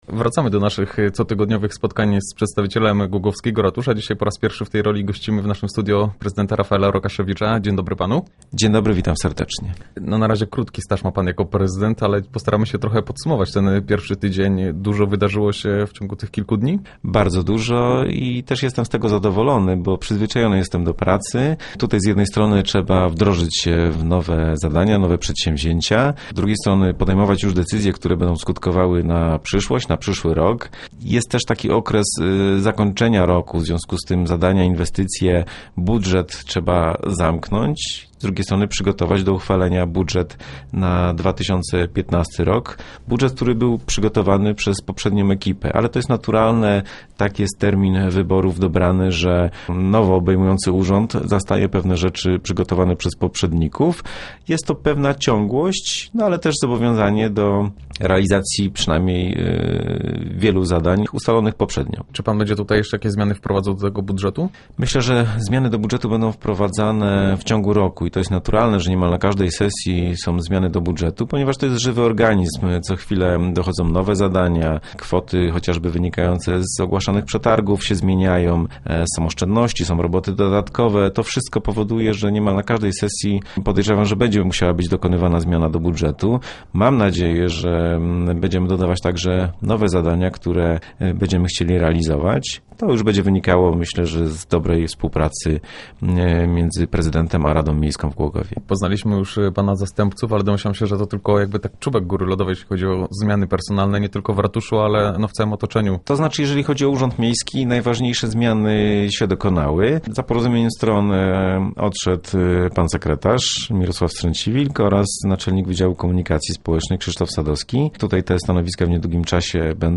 To tylko jeden z tematów rozmowy z nowym prezydentem miasta.